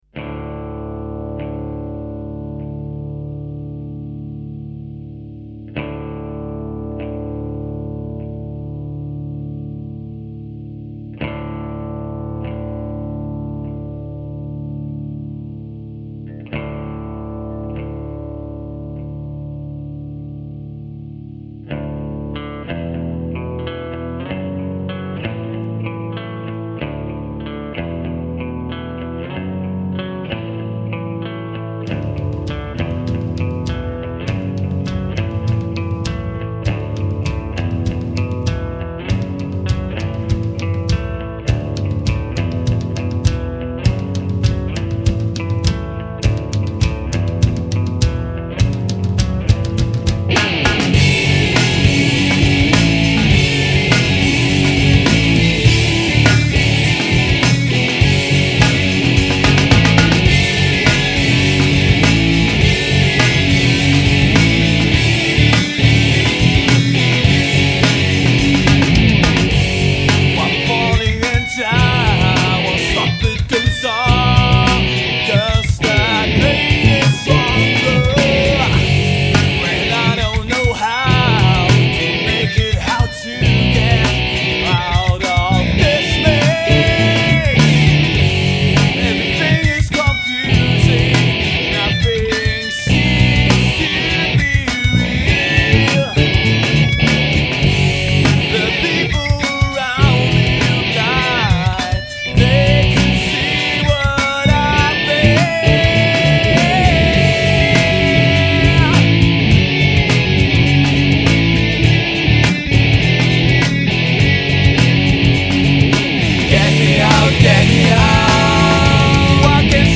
In den Songs der Band findet man neben harten Tönen viel Gefühl. Markenzeichen der Band sind ihr zweistimmiger Gesang, sowie einprägsame Melodien mit hohem Wiedererkennungswert.
Demo Songs: Get_me_out.mp3